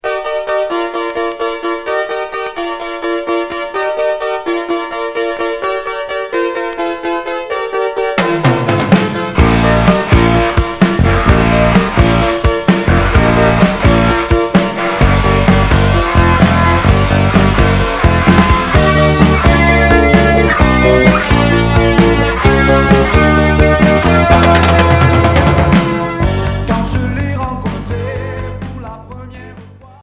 Pop rock Troisième 45t retour à l'accueil